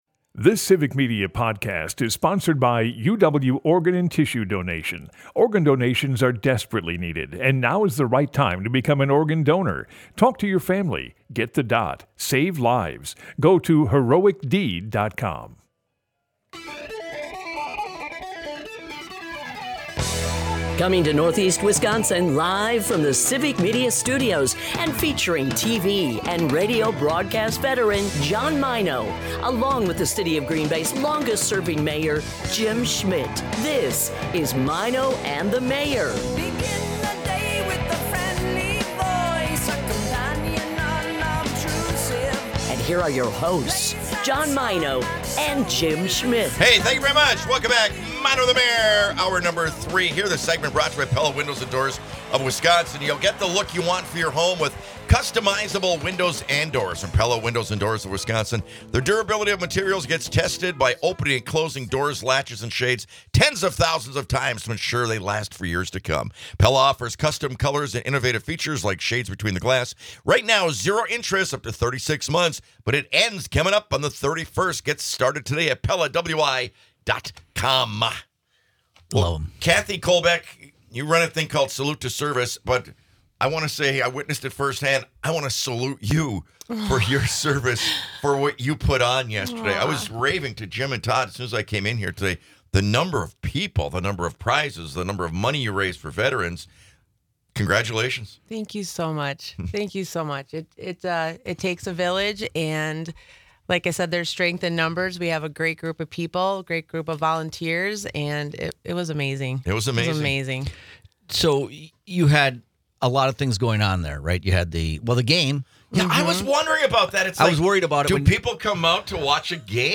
in the studio to give a brief wrap-up of yesterday's Salute to Service event. Attendees enjoyed pizza, raffle baskets, silent auction items, 50/50 raffle, and a coloring contest.